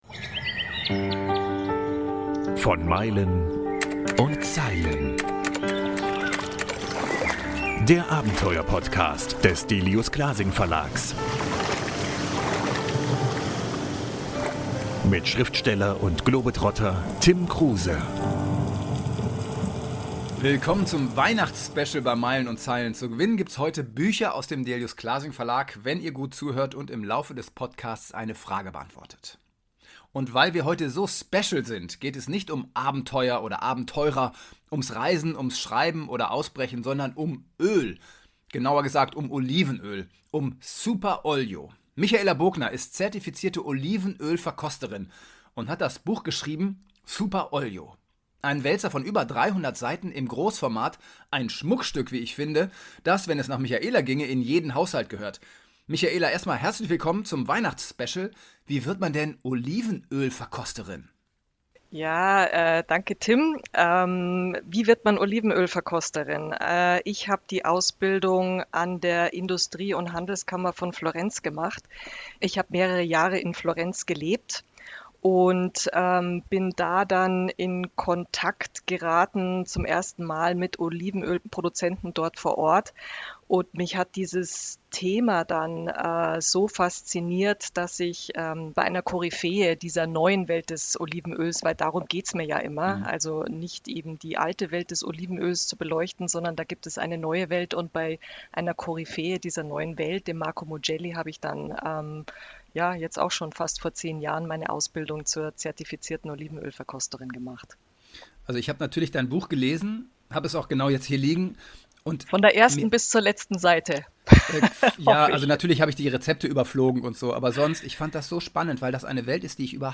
Kleiner Tipp: Die Antwort versteckt sich irgendwo im letzten Drittel des Interviews.